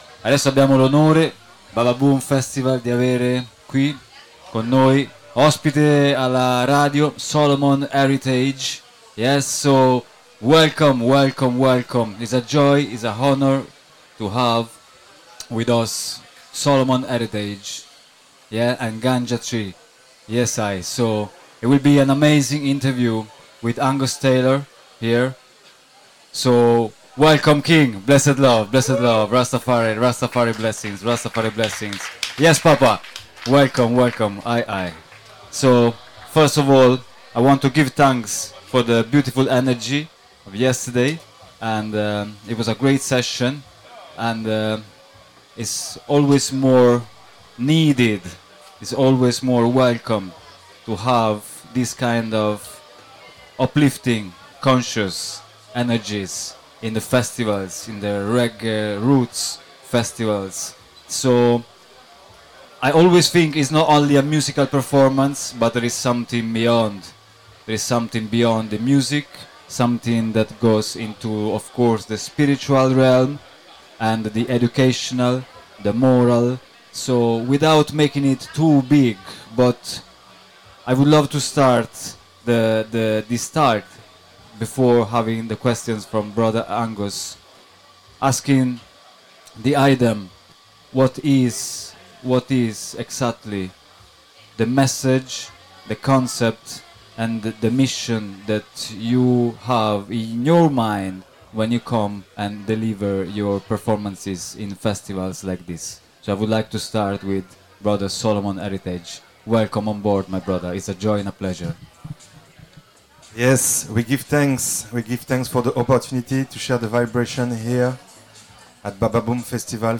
Registrata direttamente dalla Beach Yard del Bababoom Festival, l’intervista esplora:
In sottofondo, una selezione musicale che riflette l’anima del Bababoom: roots, dub, spiritual vibes e resistenza sonora .